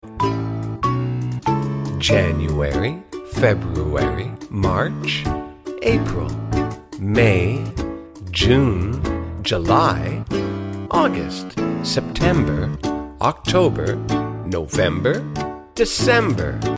I Said Awoop Jumpscare 🫡 Sound Effects Free Download